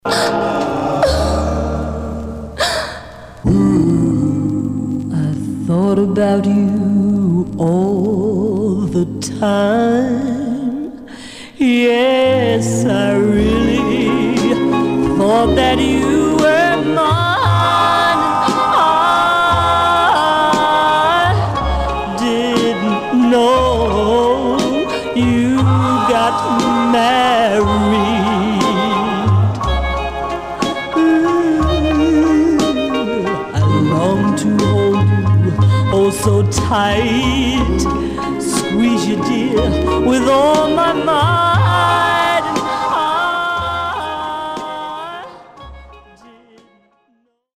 Mono
Male Black Group